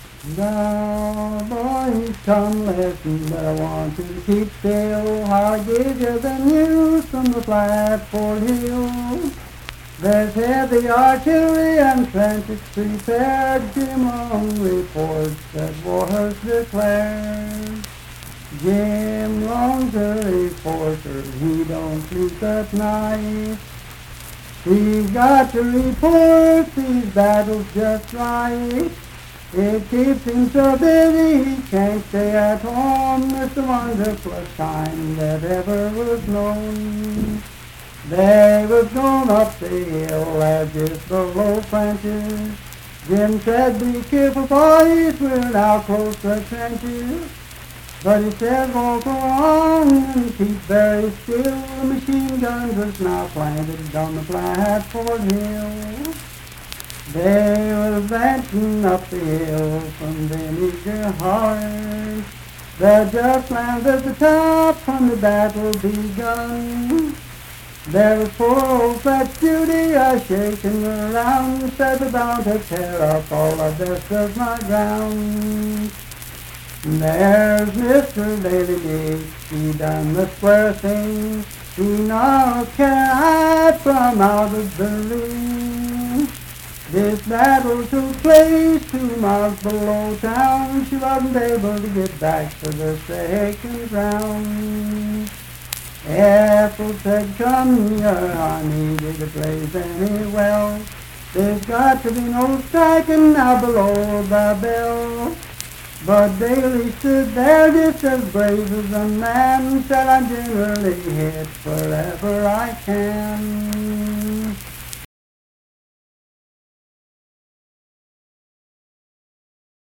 Voice (sung)
Randolph County (W. Va.)